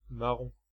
Mâron (French pronunciation: [maʁɔ̃]
Fr-Paris--Mâron.ogg.mp3